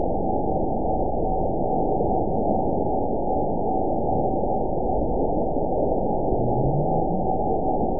event 912334 date 03/24/22 time 22:55:23 GMT (3 years, 9 months ago) score 9.58 location TSS-AB02 detected by nrw target species NRW annotations +NRW Spectrogram: Frequency (kHz) vs. Time (s) audio not available .wav